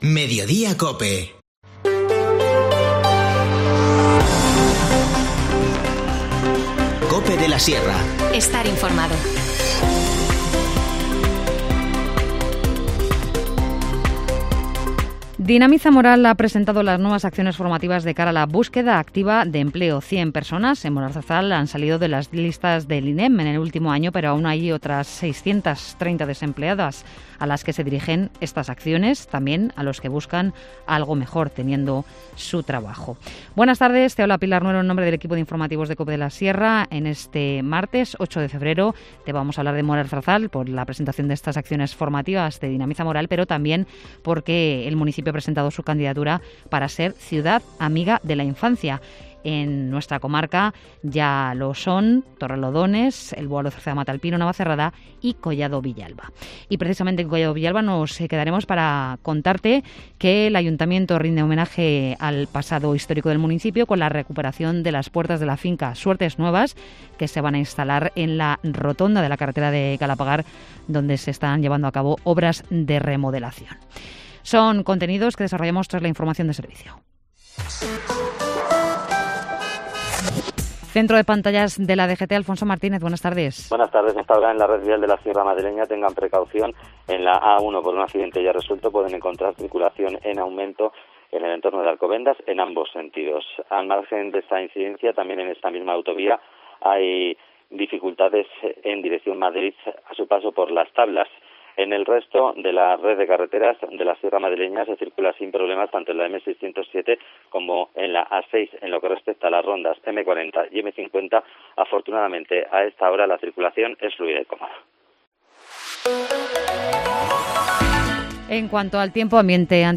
Informativo Mediodía 8 febrero